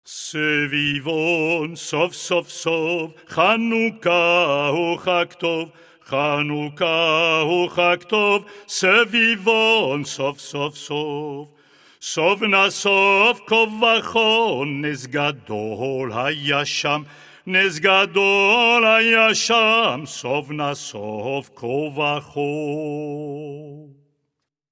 Cette chanson est traditionnellement chantée à ’Hanouka.